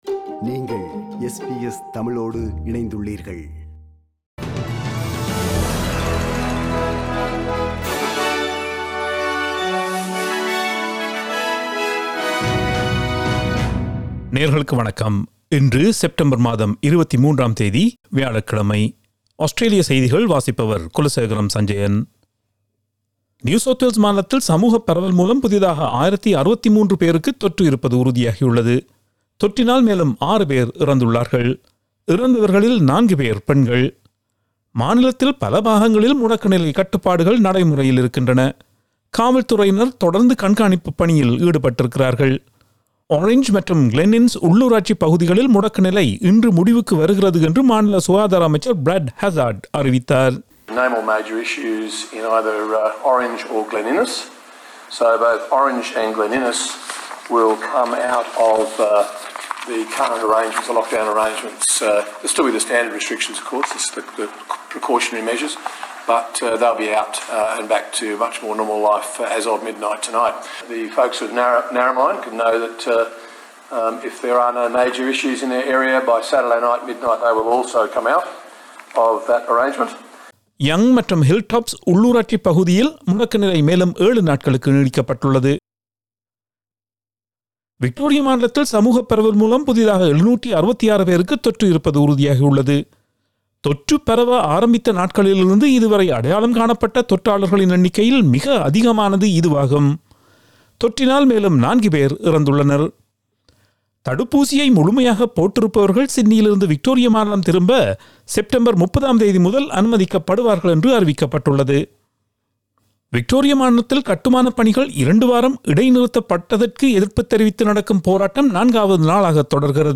SBS தமிழ் ஒலிபரப்பின் இன்றைய (வியாழக்கிழமை 23/09/2021) ஆஸ்திரேலியா குறித்த செய்திகள்.